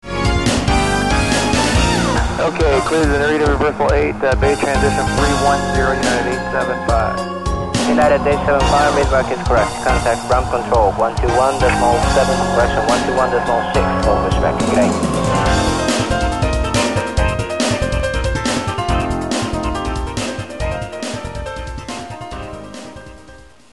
おなじみのBGMから11曲をセレクトし、クオリティの高いスタジオ音源を使用して全て再録音。
※収録曲はインストルメンタル（歌なし）バージョンです。